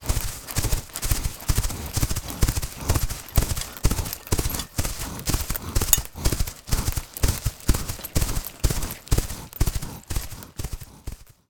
SRS_Foley_Horse_Galloping
Animal Creature Foley Gallop Horse Mammal Running sound effect free sound royalty free Animals